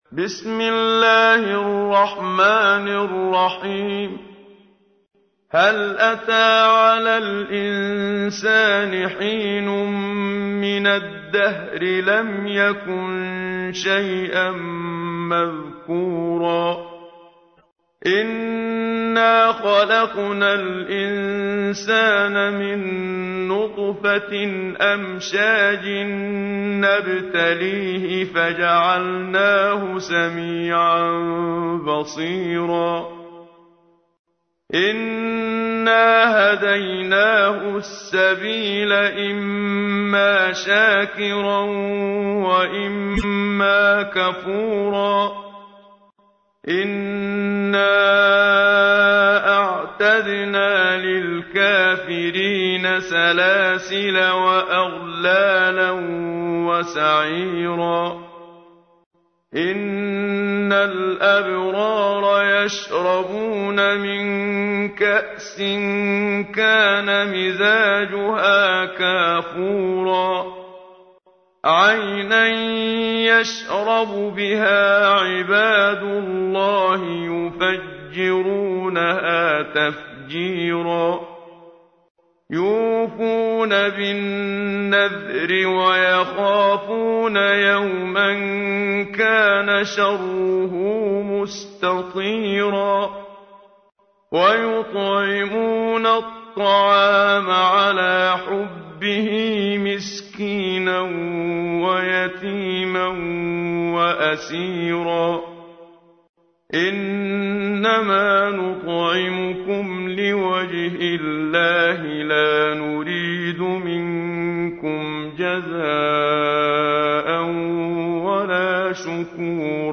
تحميل : 76. سورة الإنسان / القارئ محمد صديق المنشاوي / القرآن الكريم / موقع يا حسين